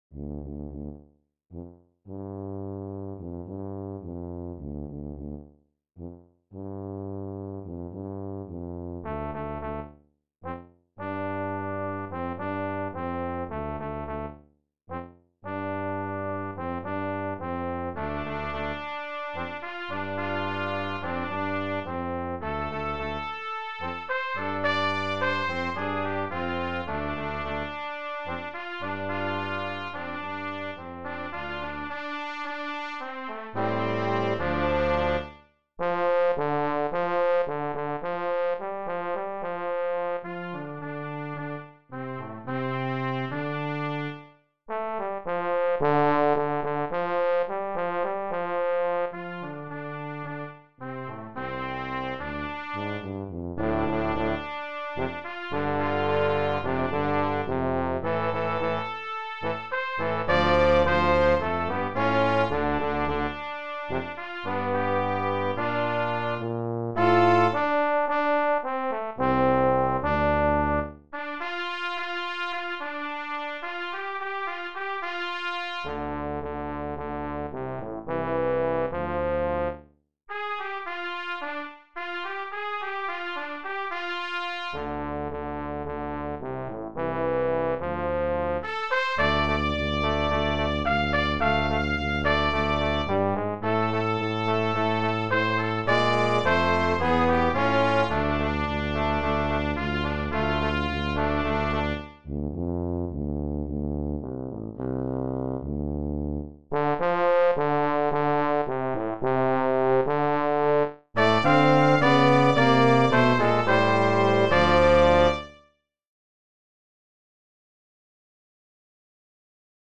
Brass Trio TTT
Traditional Spiritual